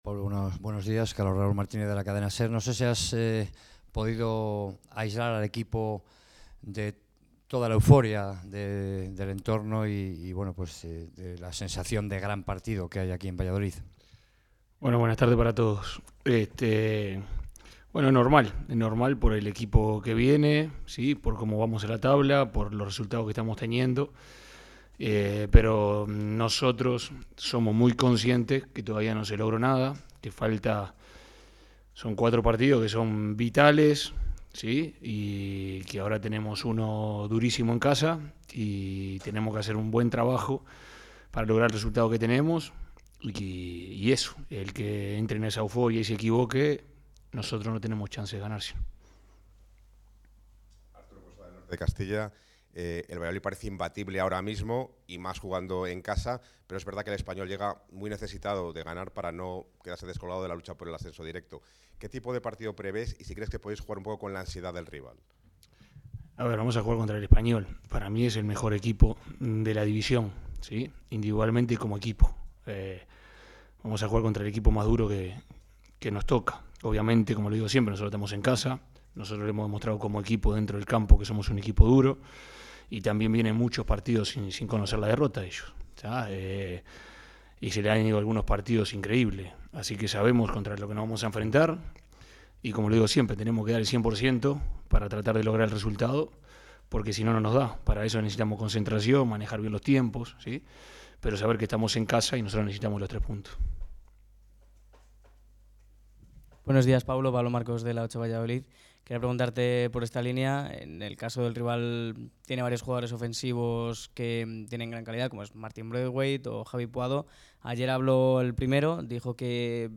Rueda de prensa de Pezzolano antes de recibir al RCD Espanyol